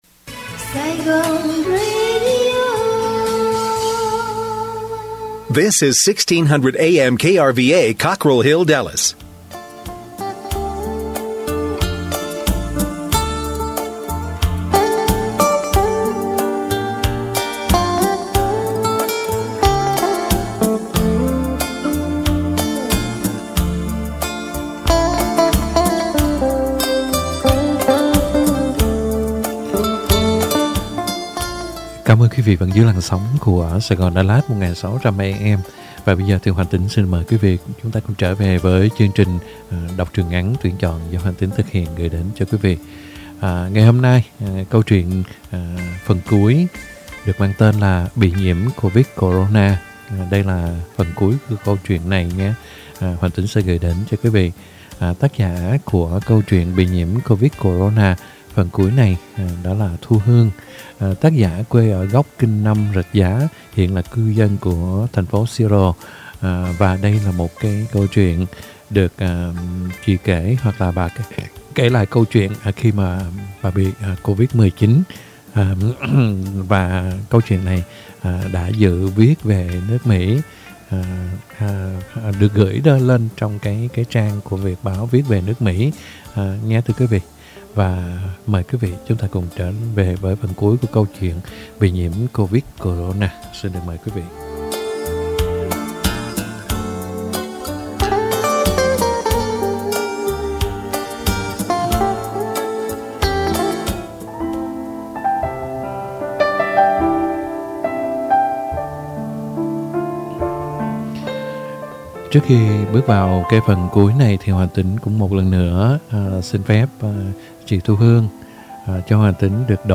Đọc Truyện Ngắn = Bị Nhiễm Covid - Corona (Phần Cuối) - 09/28/2021 .